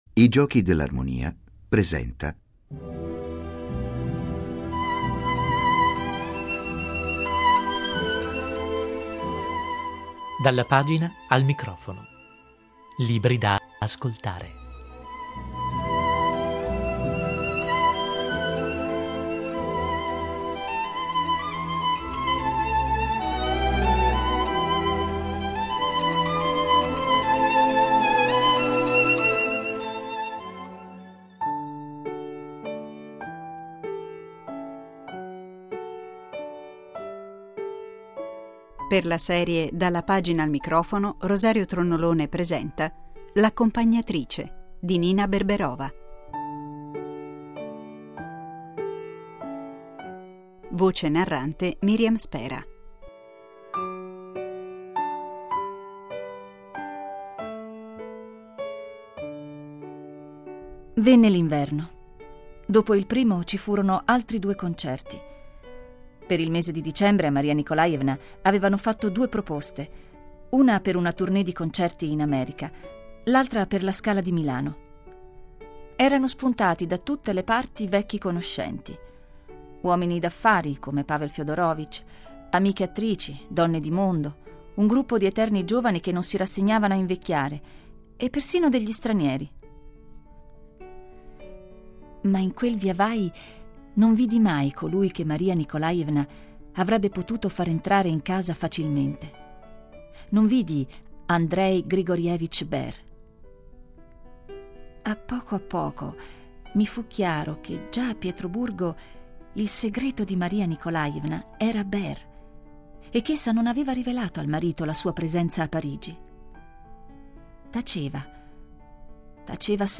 Adattamento radiofonico